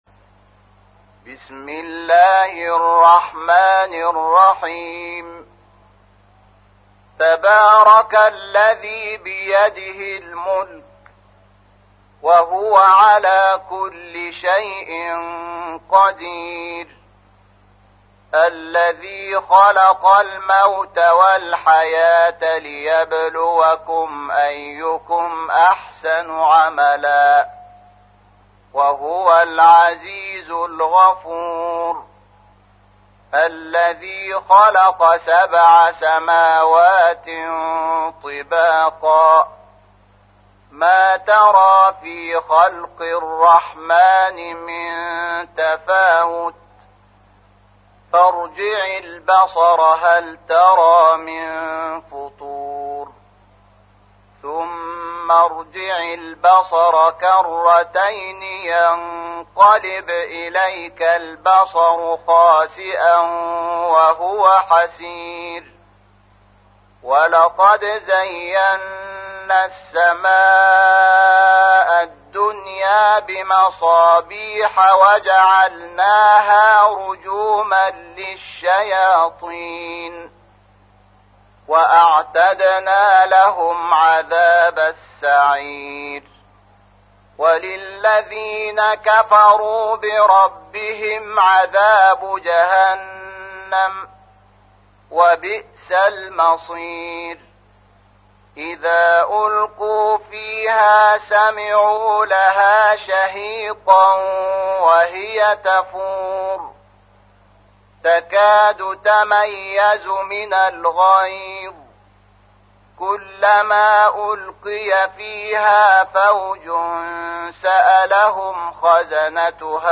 تحميل : 67. سورة الملك / القارئ شحات محمد انور / القرآن الكريم / موقع يا حسين